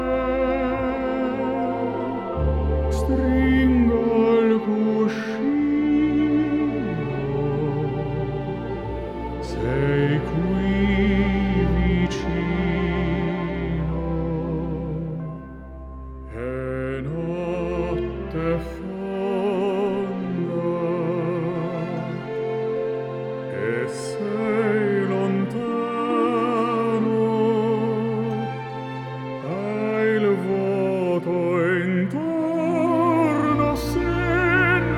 # Classical Crossover